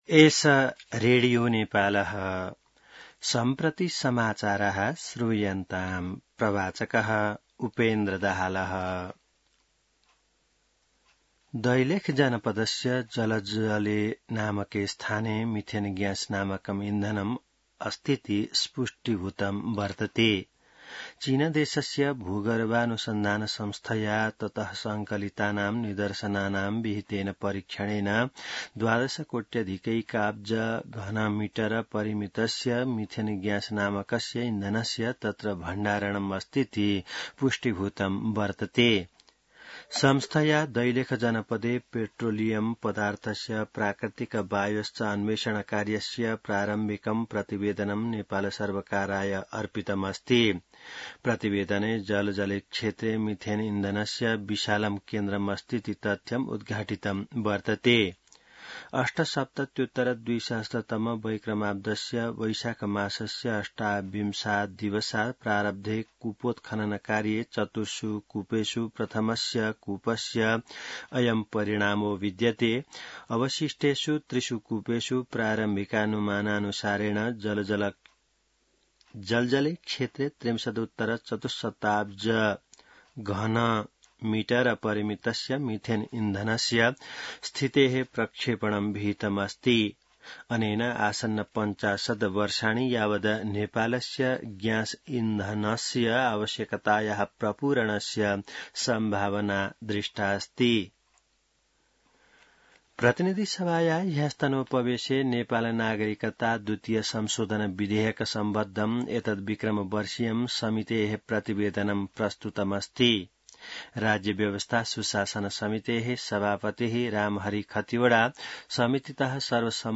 संस्कृत समाचार : ६ असार , २०८२